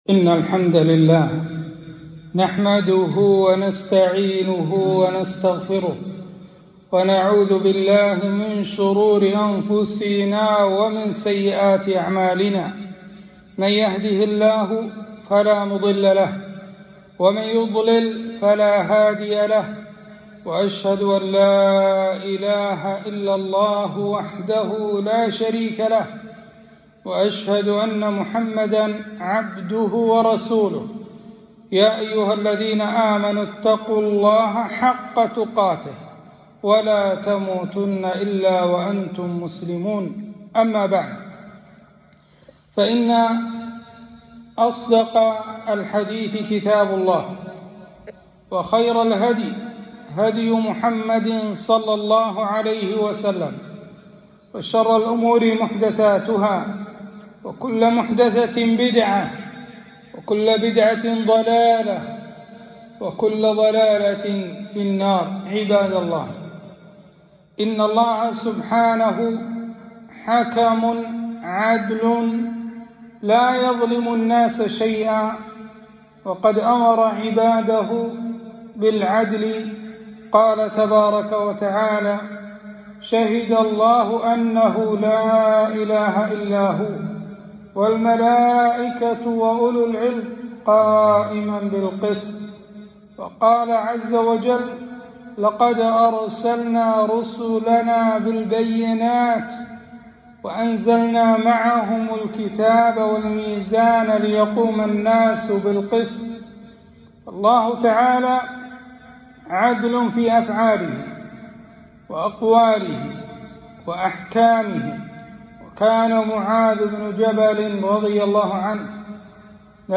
خطبة - {وتمت كلمت ربك صدقا وعدلا}